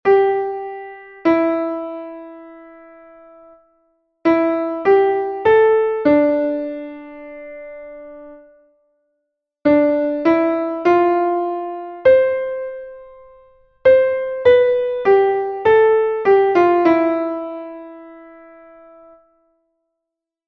largo.mp3